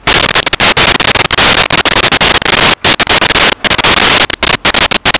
Sfx Amb Junkyard Loop
sfx_amb_junkyard_loop.wav